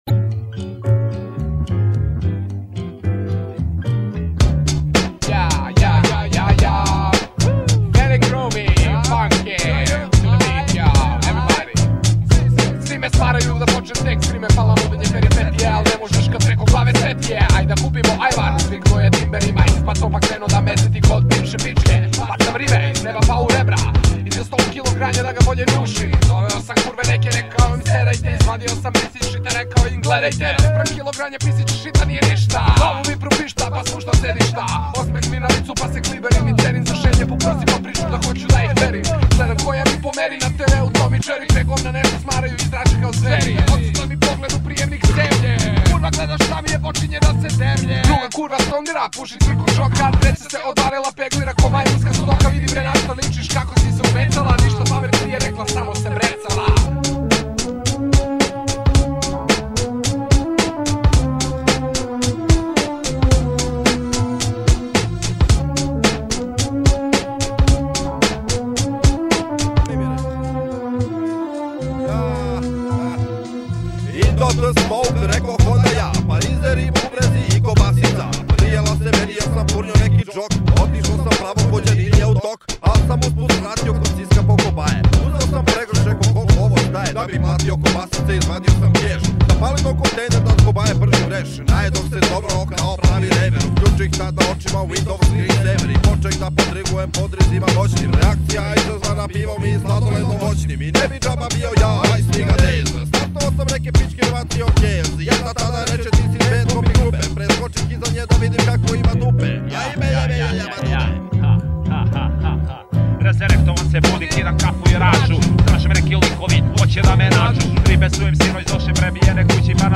muzej (ne)objavljenih pesama domaćeg repa
teško je datirati zbog podrumske produkcije vokala